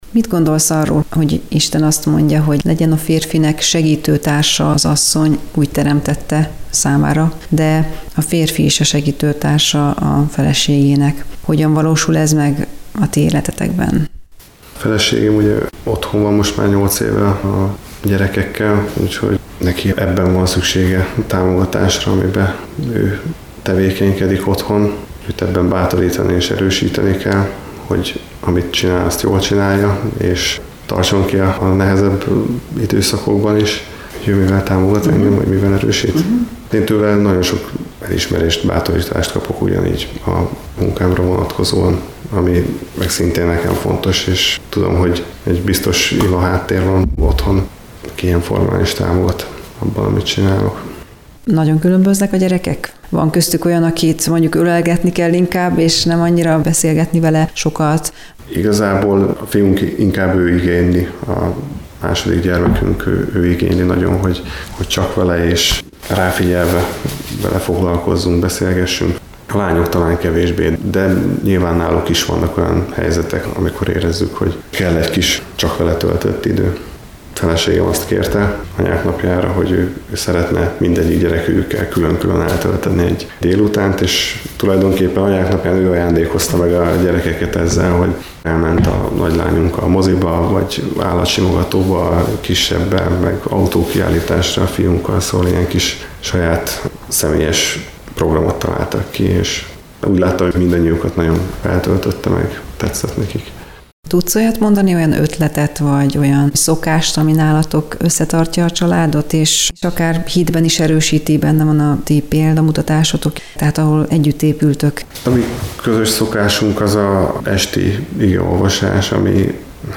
Egy fiatal családapa tanúsága